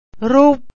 roop